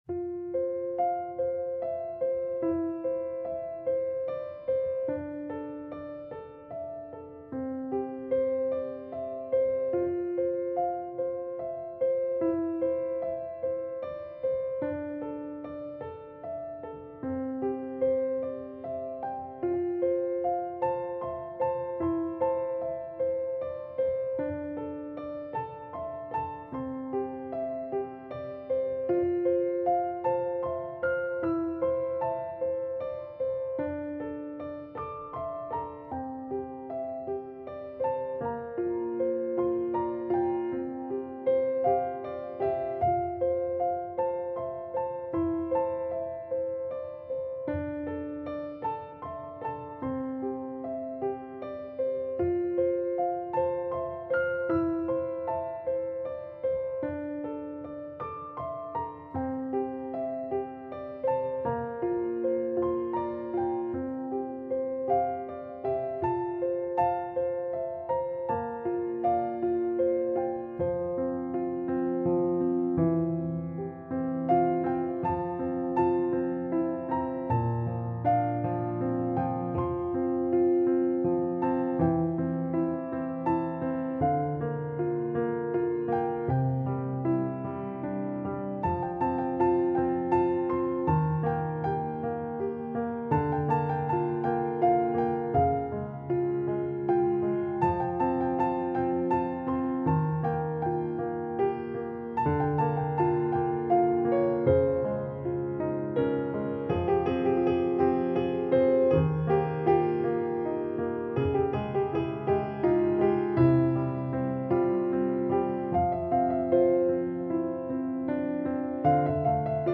a solo piano work
a 1 hour album of relaxing solo piano music.
Solo Instrument